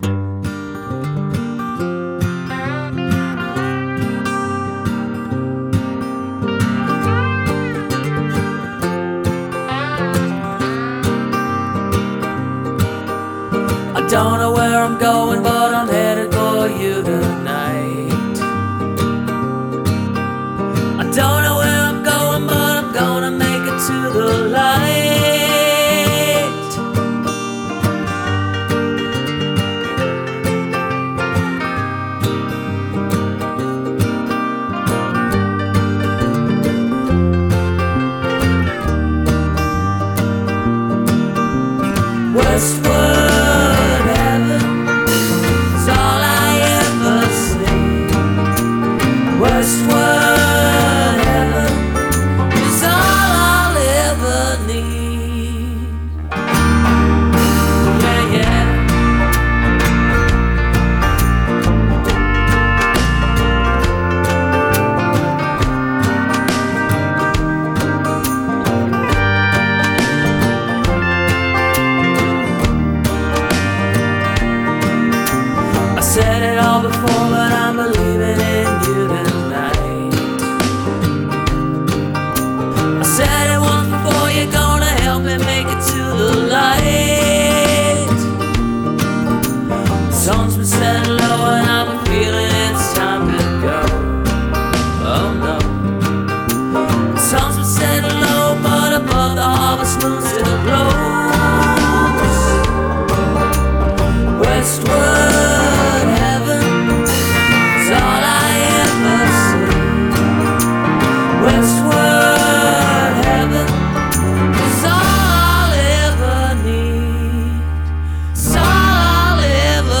avec de jolies mélodies qui nous mettent la joie au coeur.